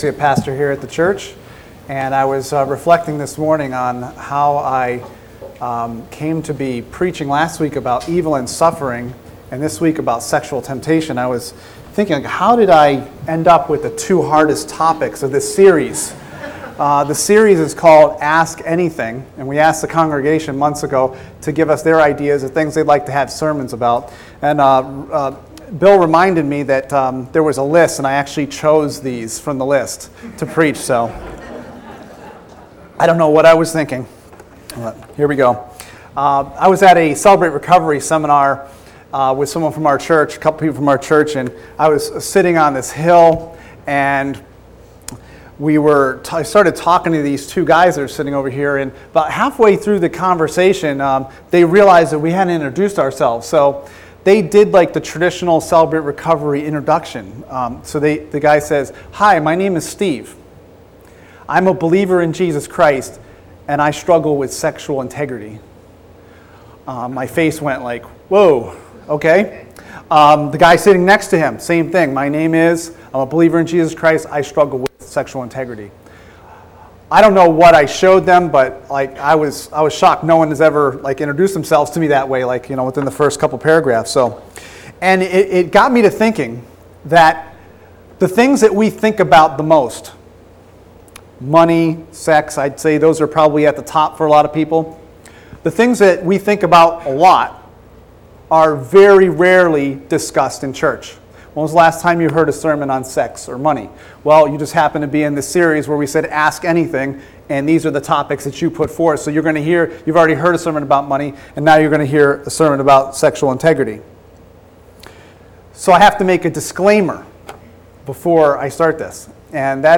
Ask Anything | Sermon Series | Crossroads Community ChurchCrossroads Community Church